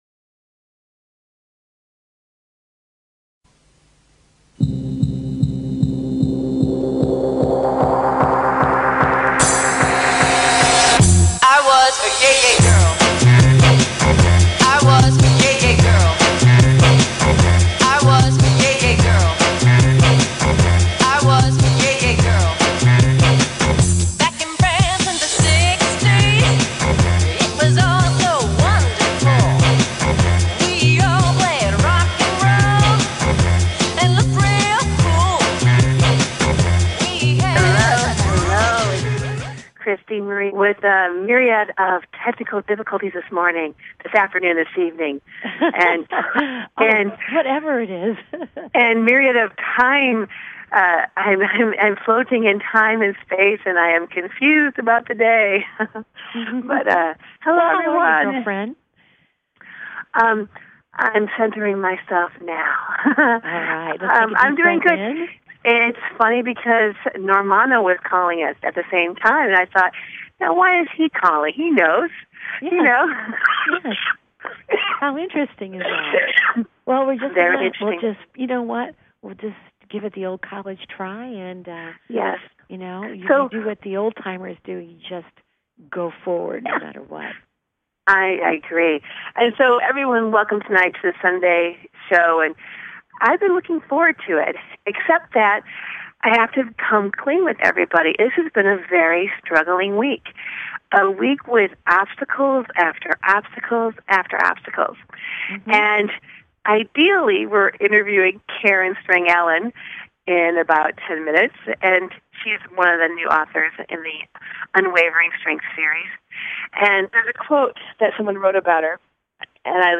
Talk Show
The conversations are candid, honest, and more so - fun!